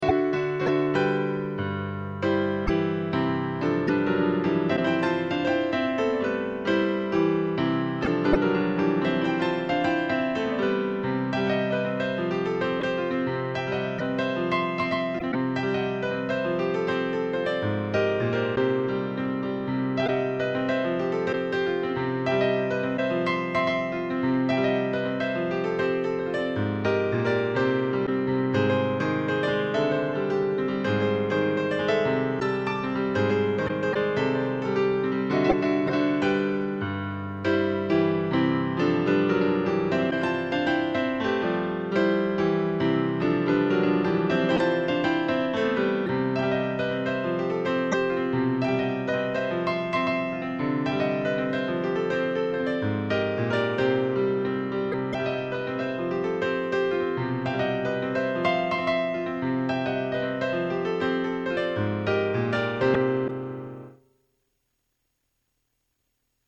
Piano
P.Sまぁ音が悪いのは、レコードのせいだと好意的に解釈してください。